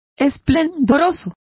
Complete with pronunciation of the translation of splendid.